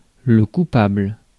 Ääntäminen
IPA : /ˈɡɪl.ti/